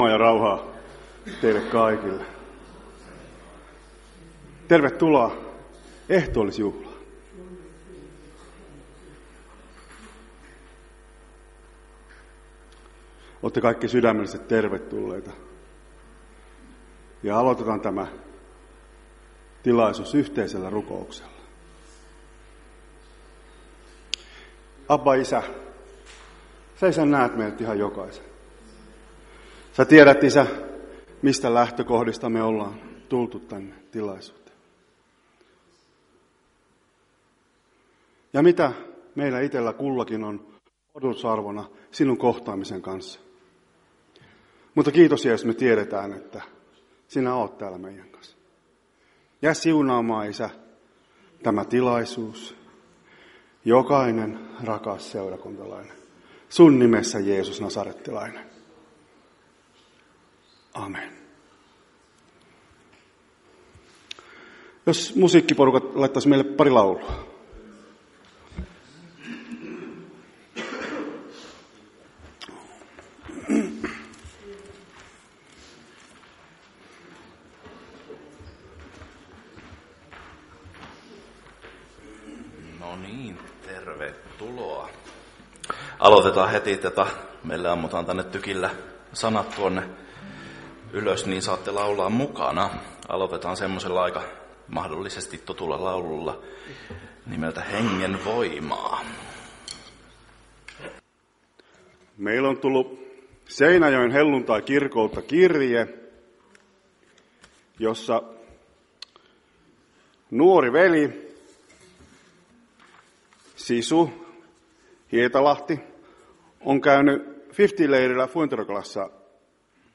Ehtoolliskokous 1.9.2024